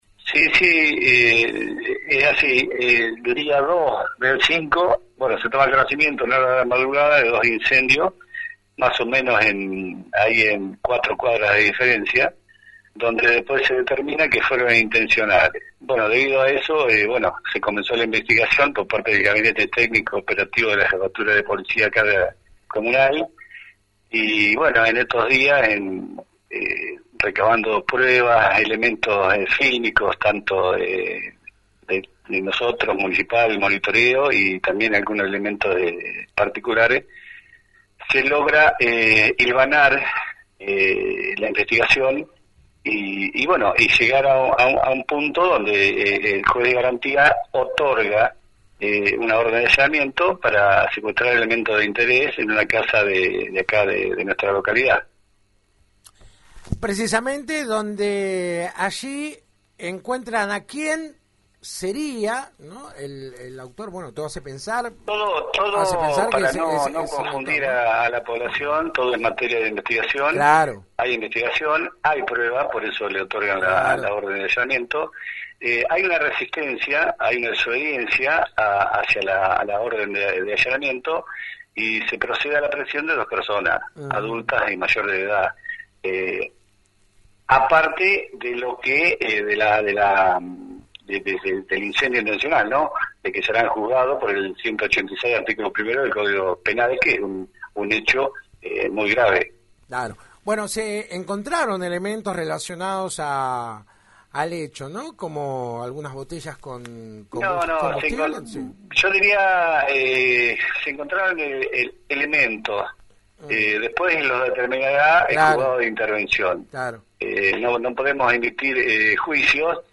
El director general de Protección Ciudadana habló este viernes en la 91.5 luego de la aprehensión de dos personas -una de ellas imputada por los incendios intencionales de la semana anterior-.